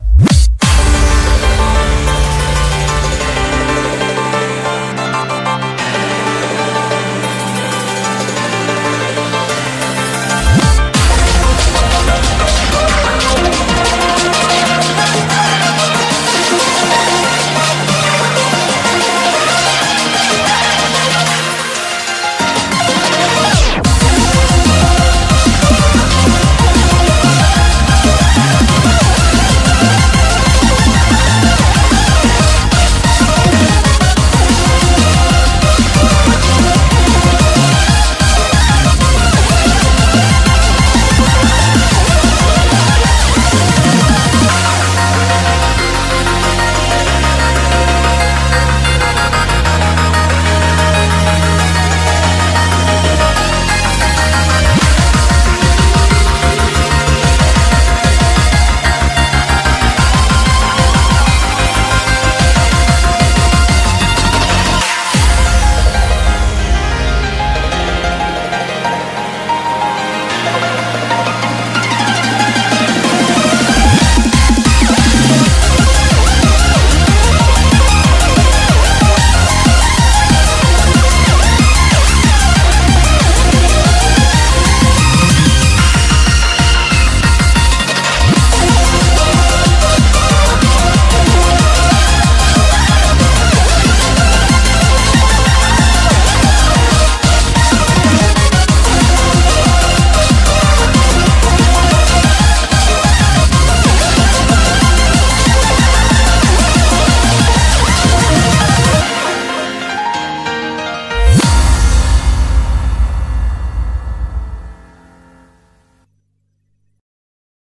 BPM186
Audio QualityPerfect (High Quality)
Commentaires[ARTCORE]